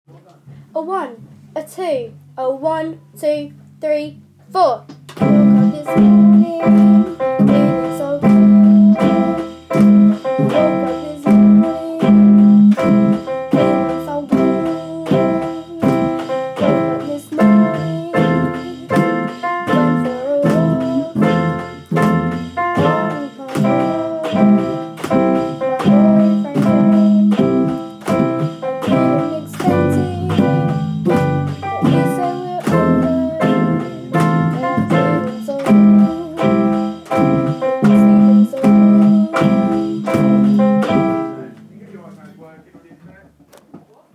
Performance of Blues Live
This is our final performance and we know it's not to great but it's something